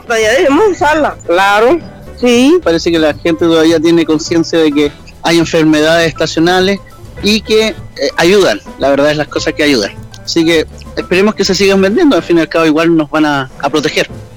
cuna-transeuntes.mp3